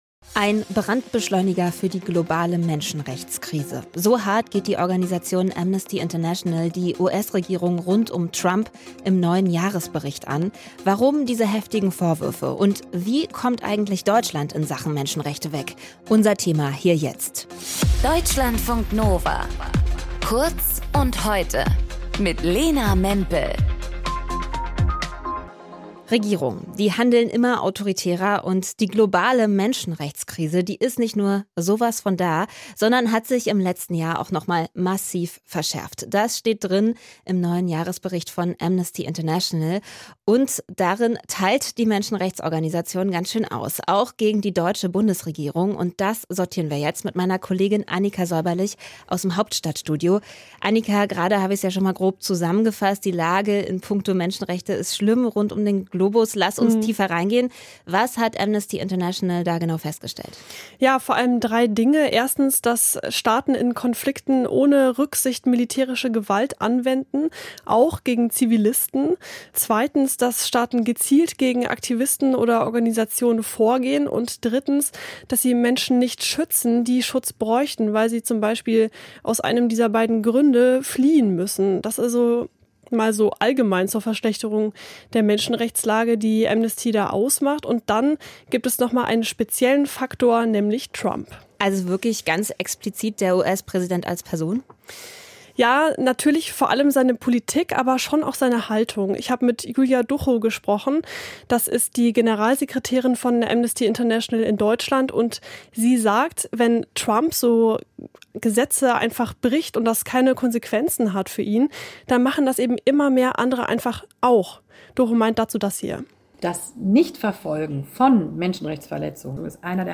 Moderation:
Gesprächspartner: